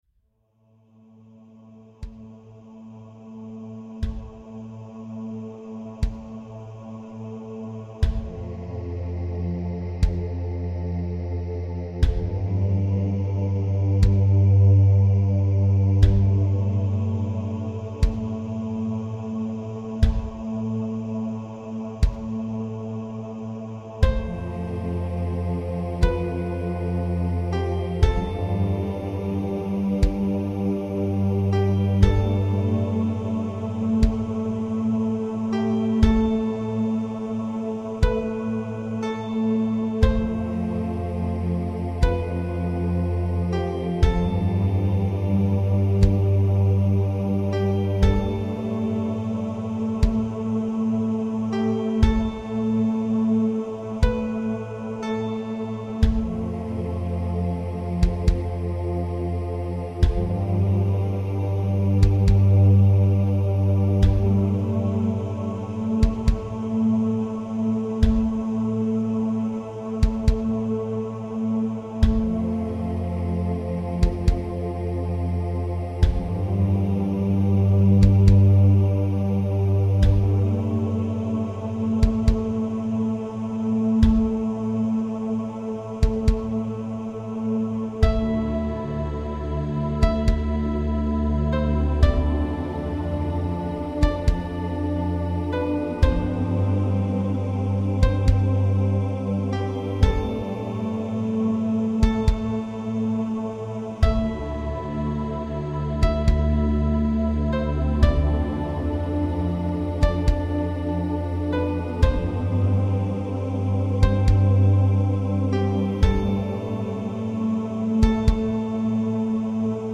Instrumental @ 2009